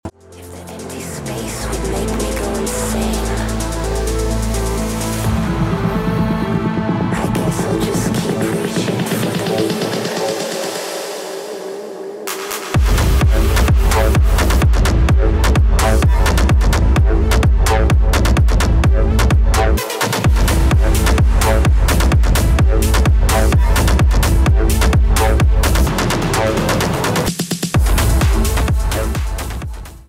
Genre : Ska.